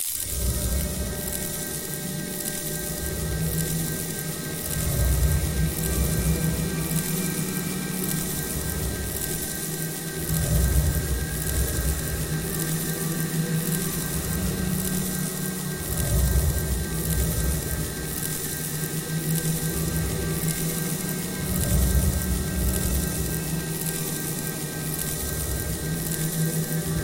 胡言乱语的人
描述：我自己制作的录音模仿了我如何想象一个来自“龙与地下城”的笨拙的Mouther听起来像。
标签： 可怕 Mouther 效果 可怕的 自语 幻想 录音 功效 地牢 FX 免打扰 声音
声道立体声